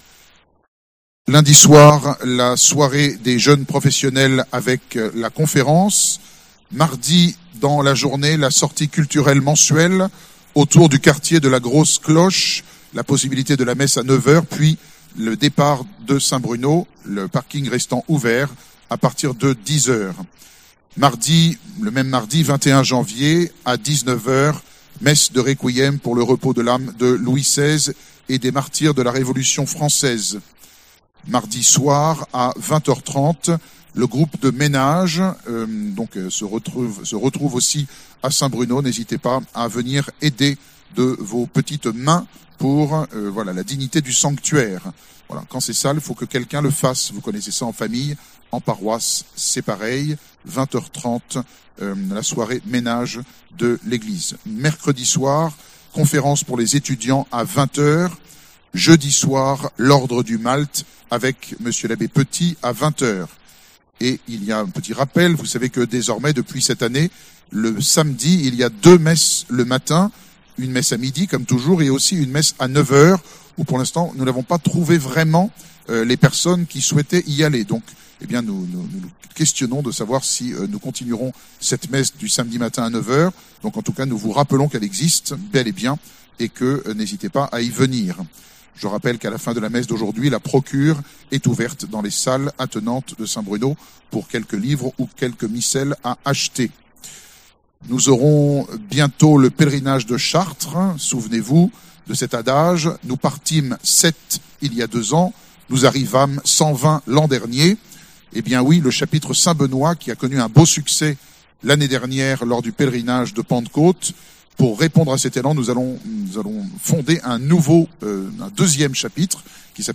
Accueil » Sermons » 2ème Dimanche après l’Epiphanie